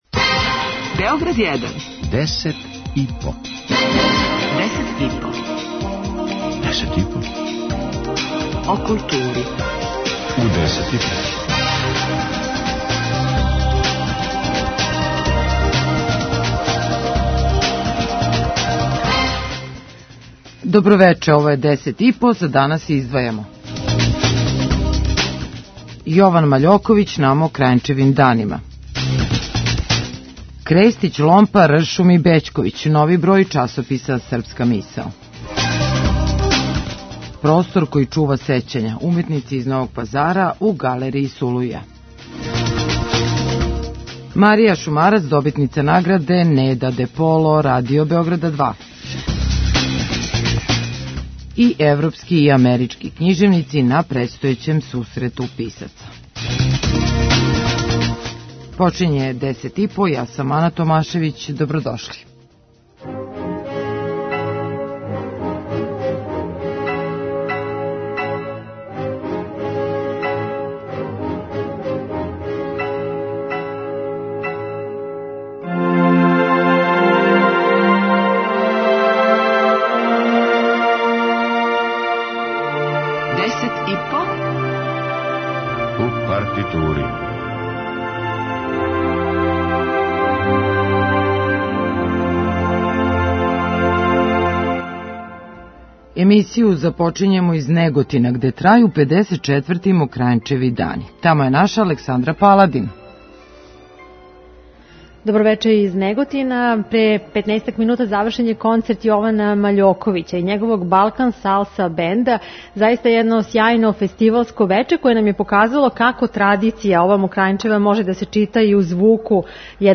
преузми : 5.33 MB Десет и по Autor: Тим аутора Дневни информативни магазин из културе и уметности.